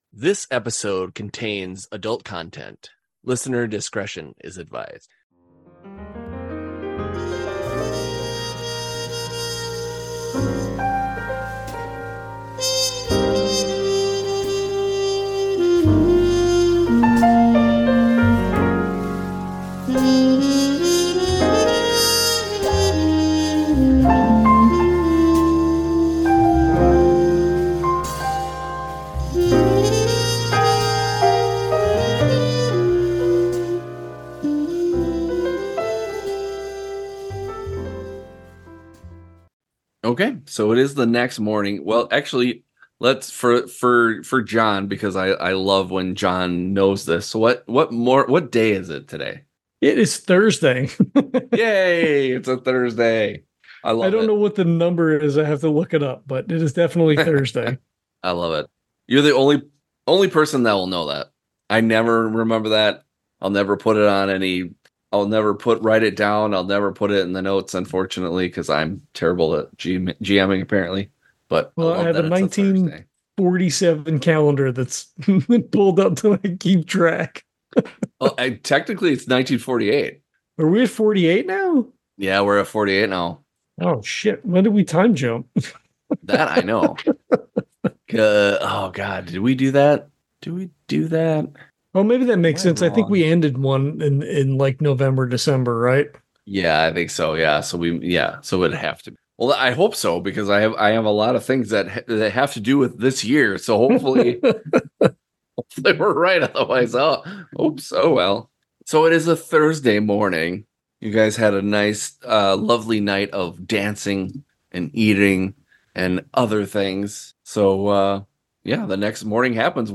Actual Play